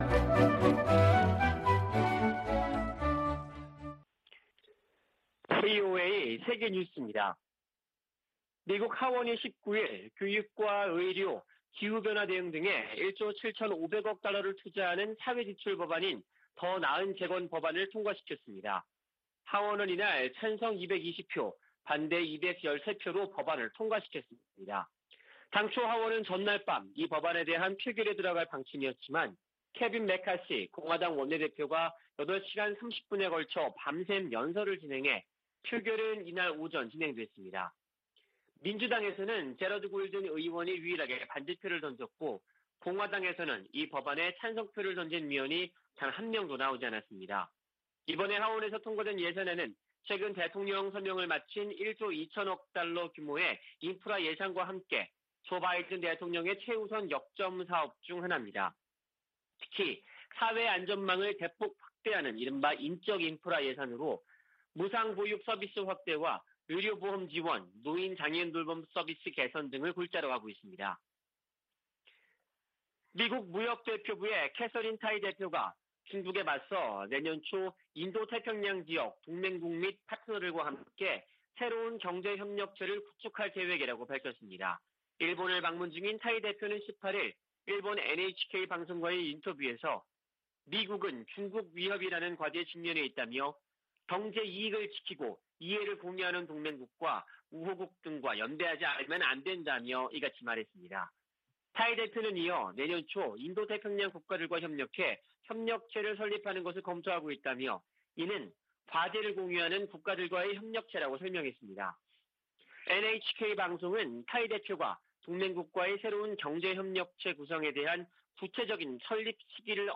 VOA 한국어 아침 뉴스 프로그램 '워싱턴 뉴스 광장' 2021년 11월 20일 방송입니다. 미국과 한국, 일본의 외교 당국 2인자들의 공동 기자회견이 무산된 데 대해 미국의 전문가들은 삼각 공조의 어려움을 보여준다고 평가했습니다. 조 바이든 미국 대통령은 베이징 동계올림픽의 ‘외교적 보이콧’을 고려하고 있다고 밝혔습니다. 중국은 북한과 함께 미-한 동맹의 결속력을 끊임없이 시험할 것이라고 전직 주한 미국대사가 밝혔습니다.